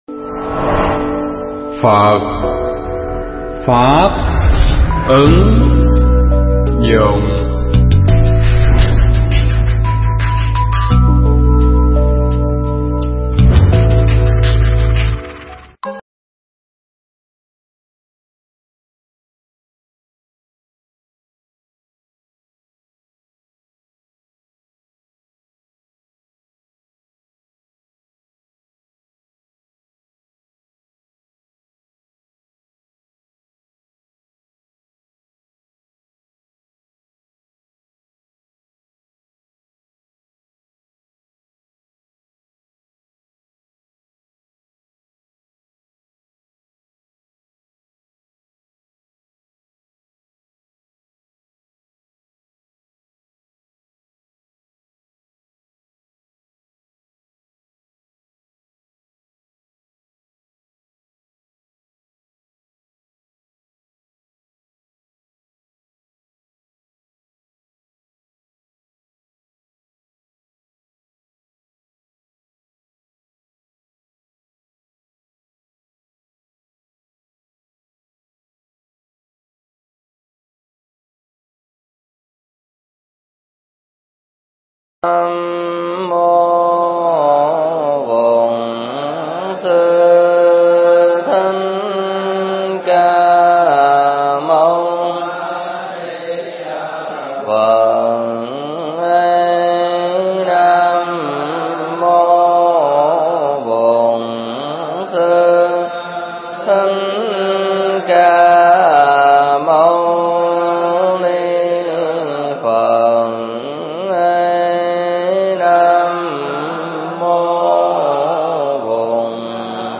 pháp thoại
giảng tại trường hạ tu viện Tường Vân